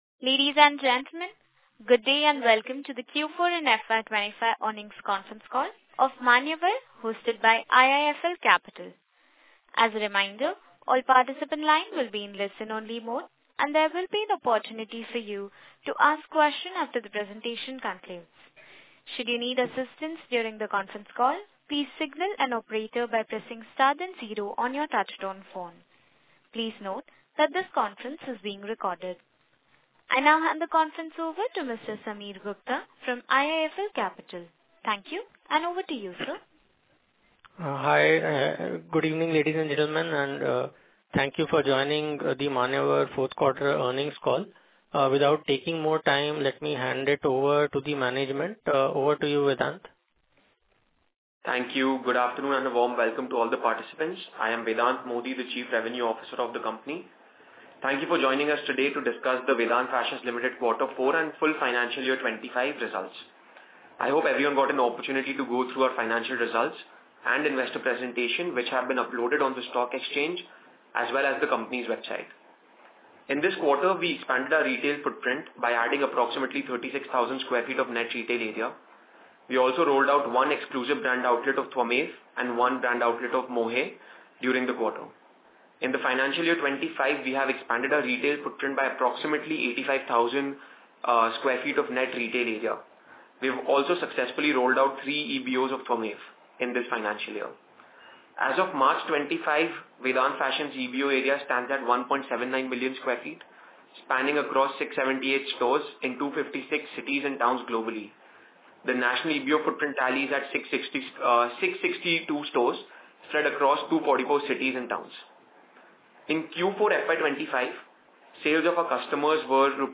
Audio Recording of the Earnings Call Dated 29.01.2024